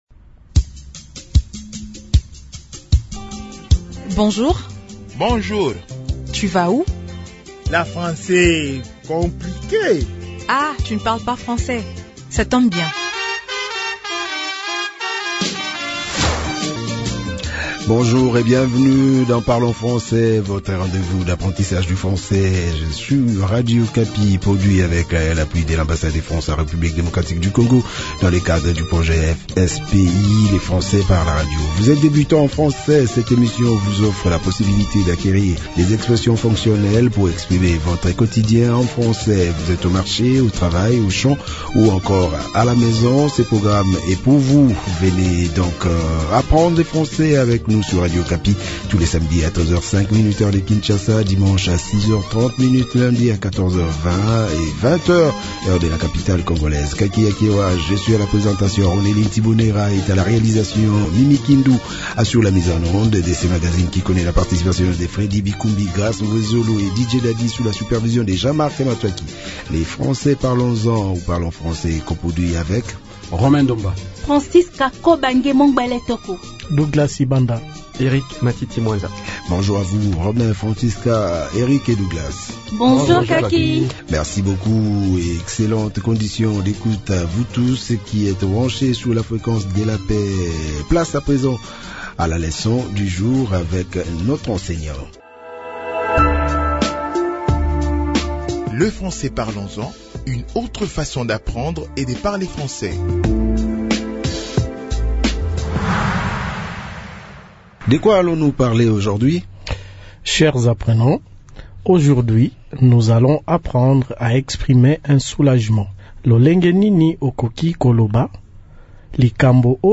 Pas de problème, Radio Okapi met à votre disposition cette nouvelle leçon pour apprendre cela facilement. Dans ce numéro, retrouvez des phrases adaptées à votre niveau pour apprendre avec aisance à parler français.